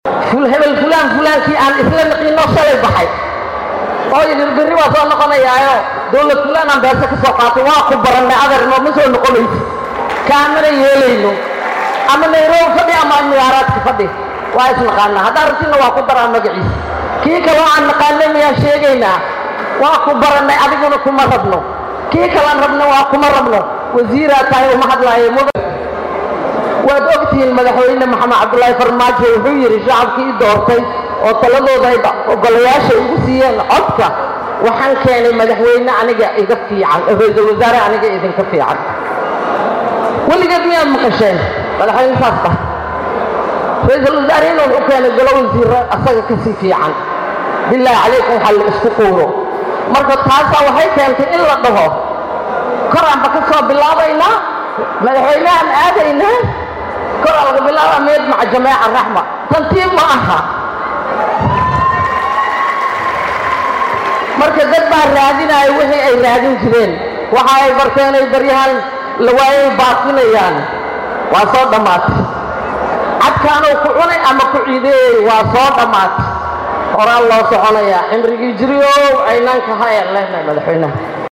Wasiir Kahdiija ayaa hadalkaan waxa ay ka sheegtay Munaasabad lagu soo bandhigayay Waxqabadka xukuumadda oo xalay lagu qabtay Magaalada Muqdisho.
Hoos Ka Dhageyso Codka Wasiir Khadiijo.